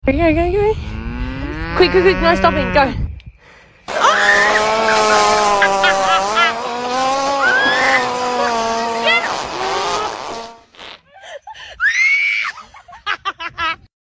Girl Is Riding A Black sound effects free download
Girl Is Riding A Black Angus Fat Cow (Cow Biggest Fart Version)